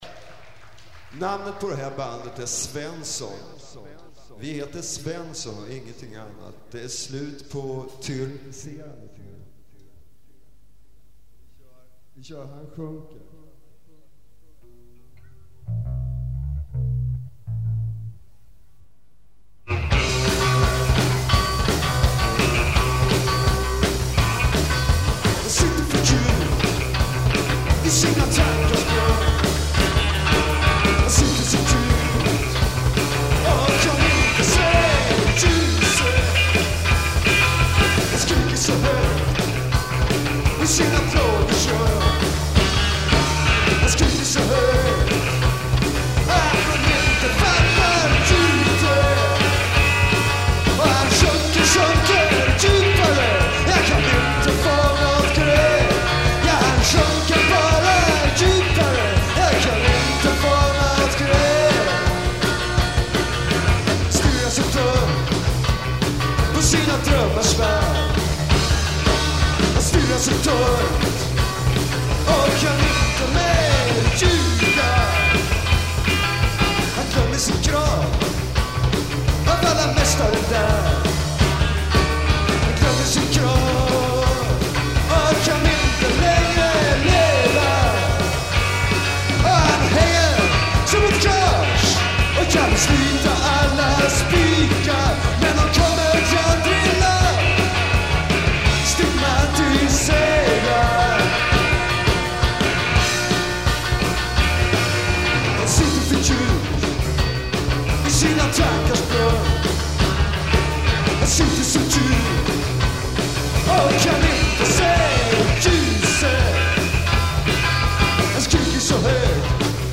Bass
Guitar
Drums
At Arbis, November 9, 1983